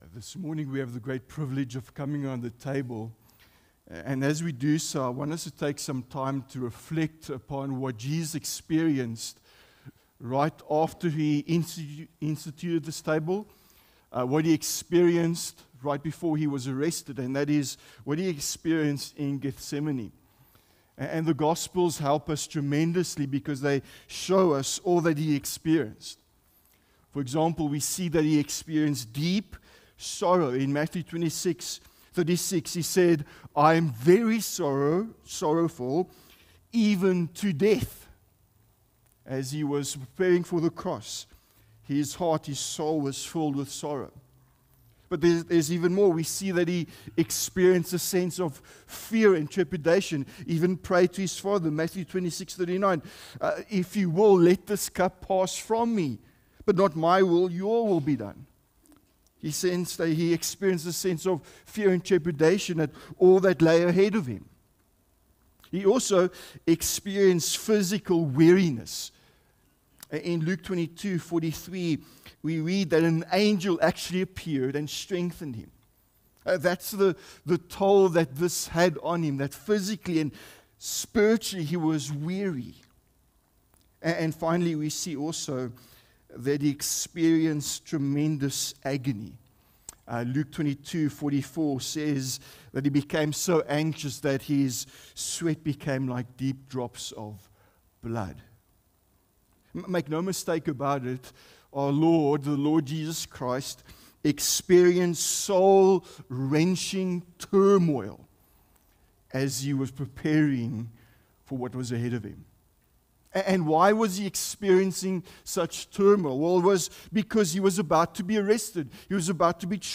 Sermons | Honeyridge Baptist Church